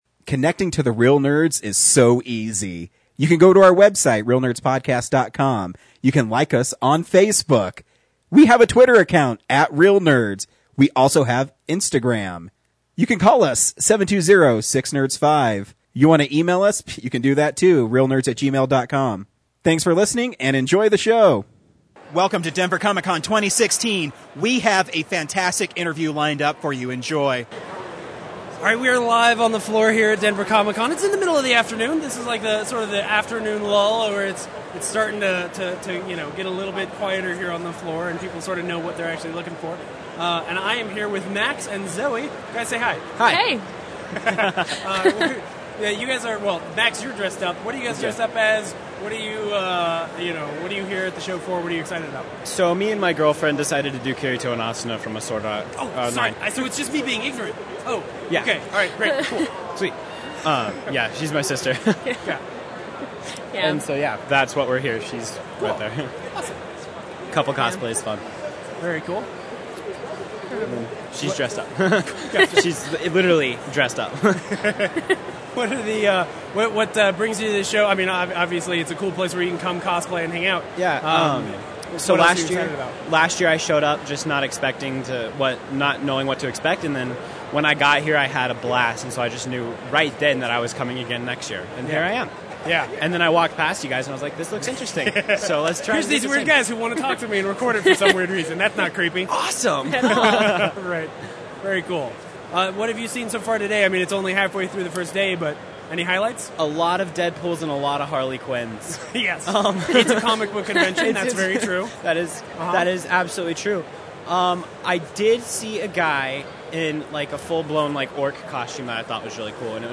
Reel Interview